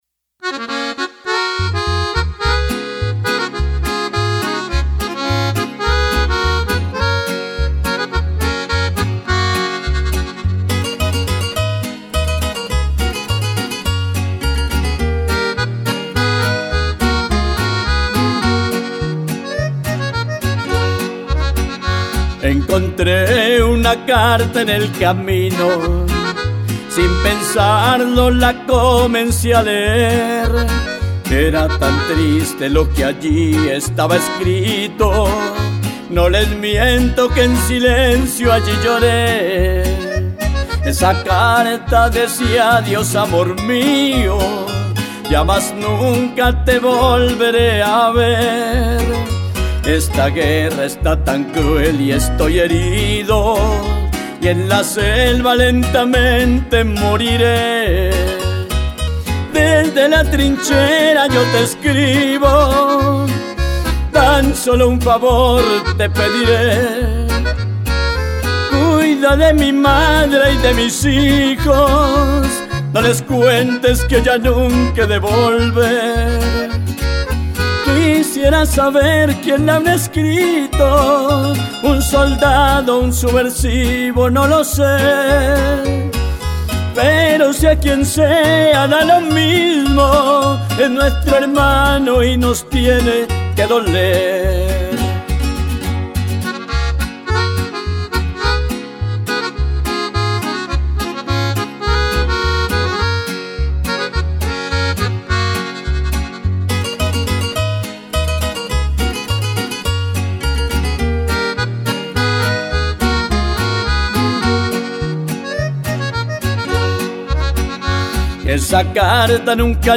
Canción
voz.
cordeón.
guitarra.
vihuela y cuatro.
trompeta.
bajo.
requinto.